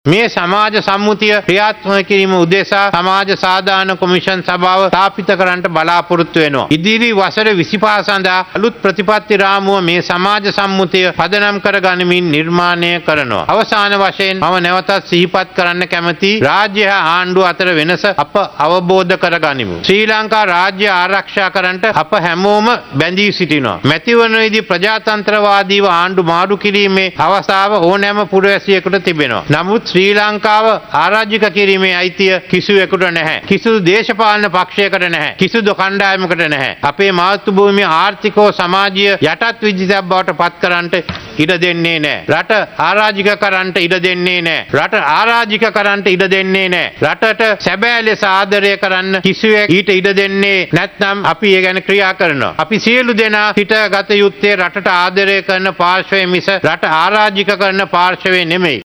එමෙන්ම රට අරාජික කිරීමට කිසිවෙකුට ඉඩ නොදෙන බවයි ජනාධිපති රනිල් වික්‍රමසිංහ මහතා ආණ්ඩුවේ ප්‍රතිපත්ති ප්‍රකාශය ඉදිරිපත් කරමින් වැඩිදුරටත් පැවසුවේ.